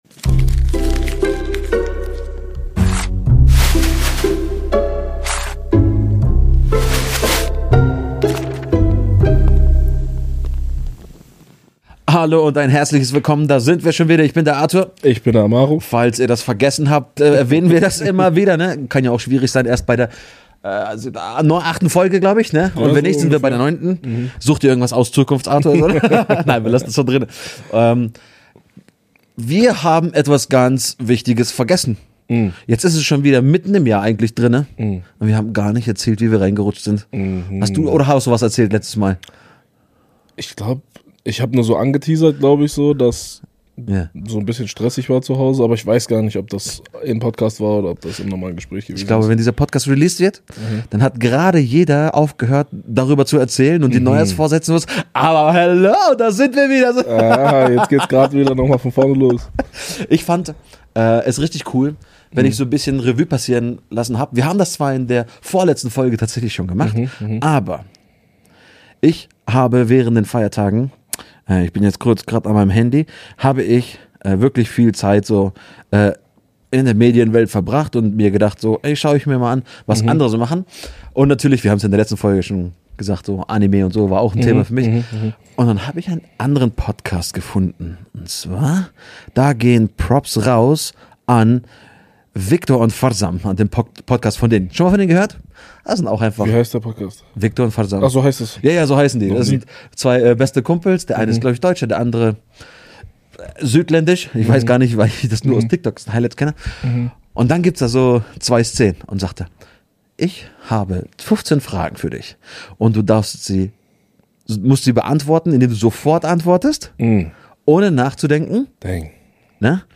Sie ist ein Gespräch über Risse im Leben — und darüber, was durch sie hindurchscheint.